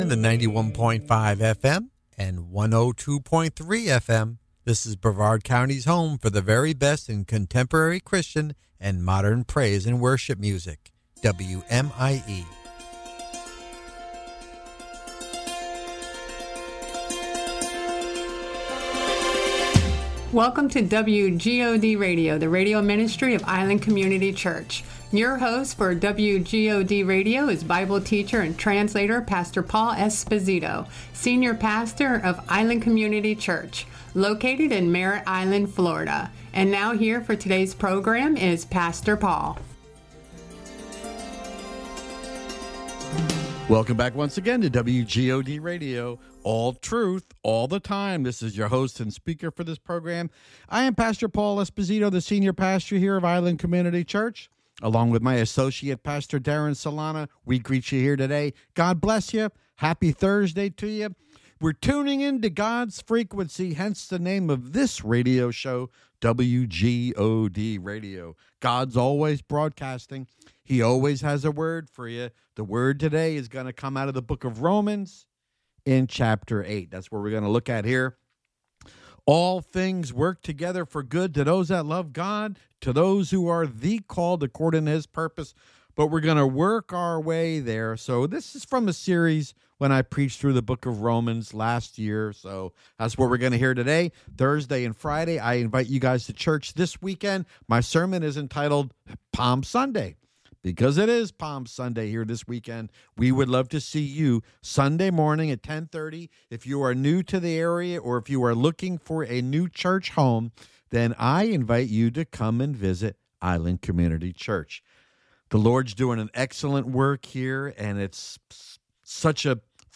Sermon: "God is For Me" Romans Ch. 8 Part 1